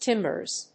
/ˈtɪmbɝz(米国英語), ˈtɪmbɜ:z(英国英語)/